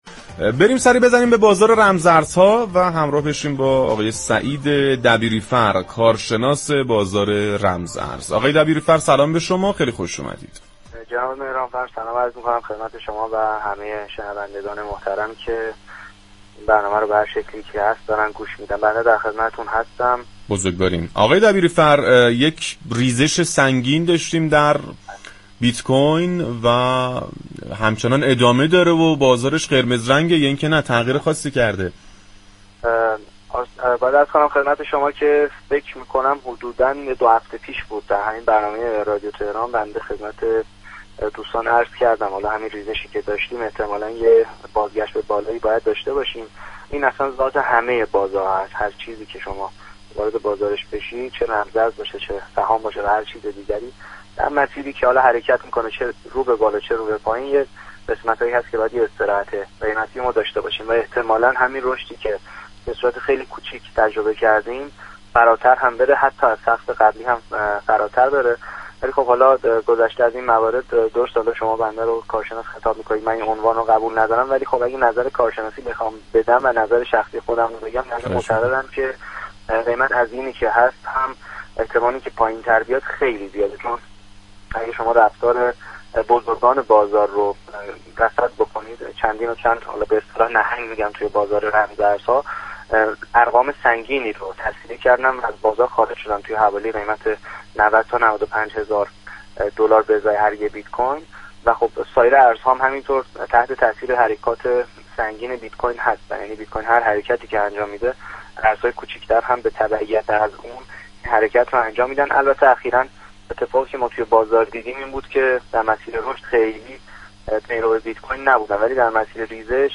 گفتگو با برنامه «بازار تهران» رادیو تهران